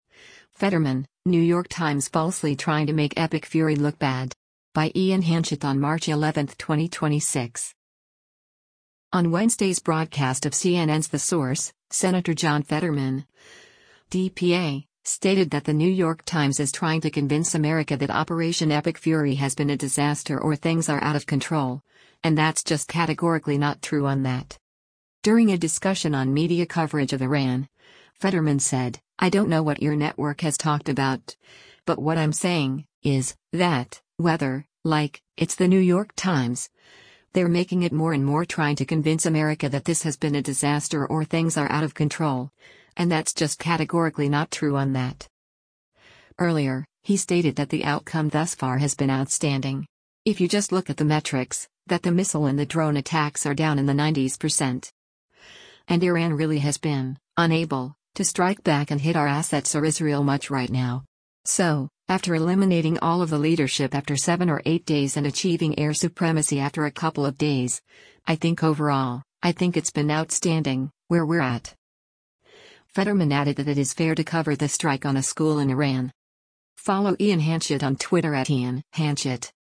On Wednesday’s broadcast of CNN’s “The Source,” Sen. John Fetterman (D-PA) stated that The New York Times is “trying to convince America” that Operation Epic Fury “has been a disaster or things are out of control, and that’s just categorically not true on that.”